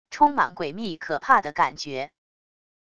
充满诡秘可怕的感觉wav音频